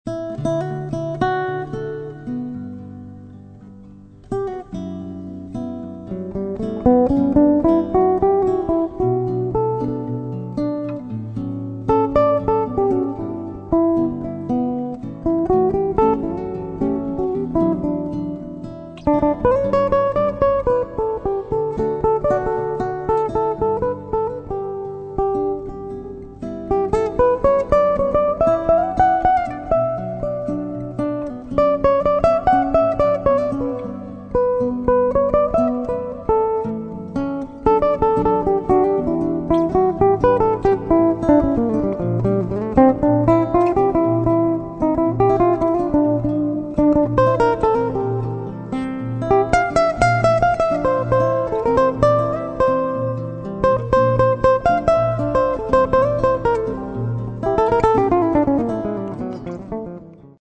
Registrato e missato presso il Midi Groove Studio (Napoli)